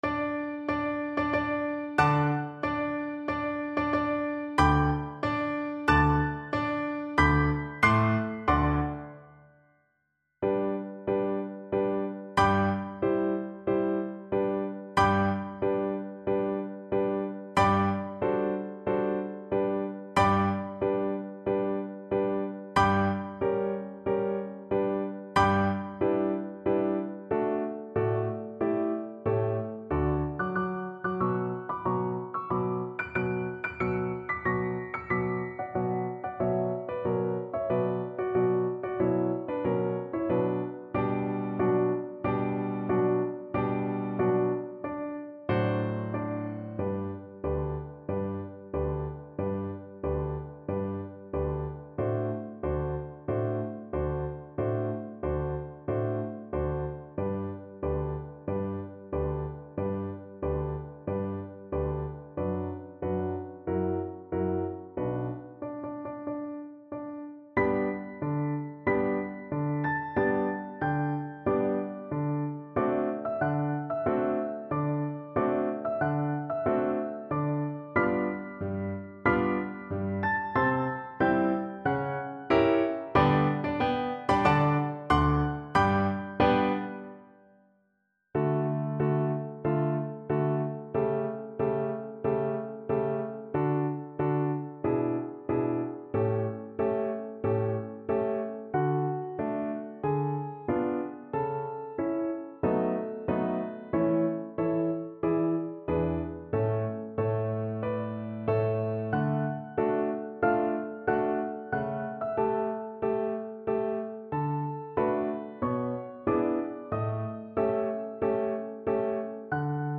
Cello version
Allegro marziale (View more music marked Allegro)
4/4 (View more 4/4 Music)
Classical (View more Classical Cello Music)